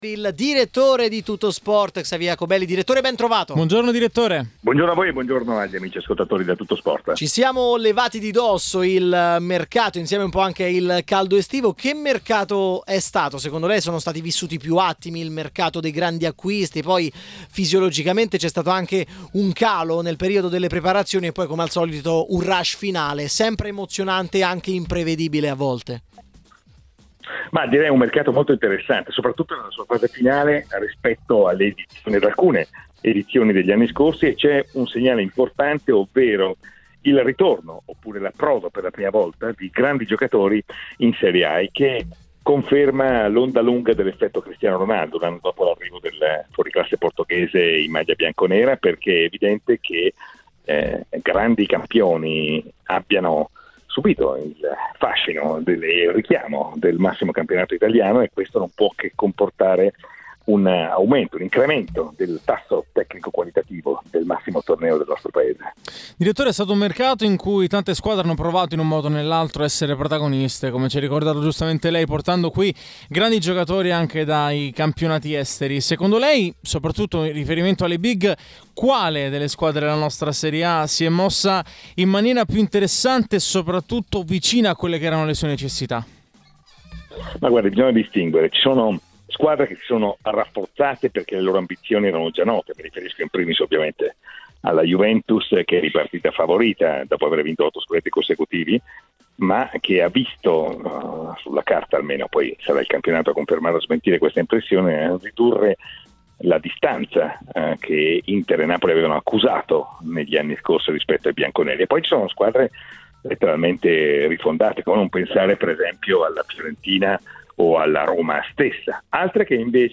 intervenuto ai microfoni di tmwradio © registrazione di TMW Radio